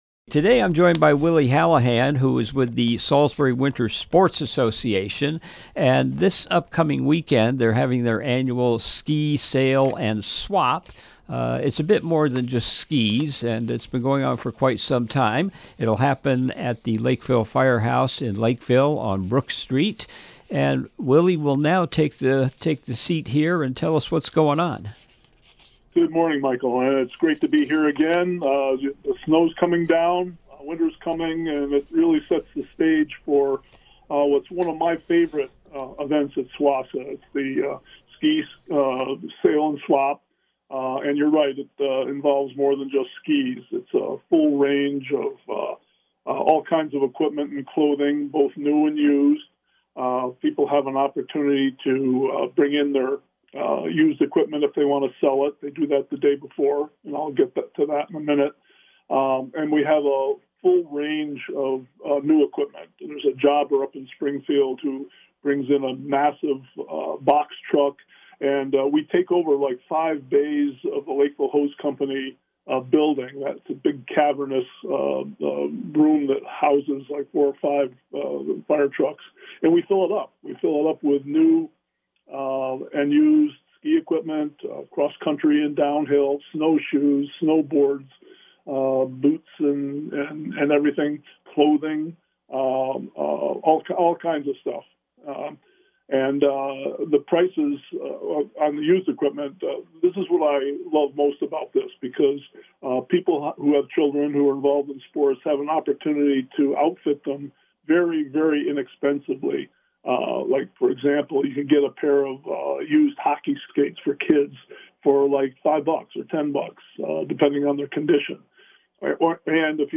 ROBIN HOOD RADIO INTERVIEWS Interview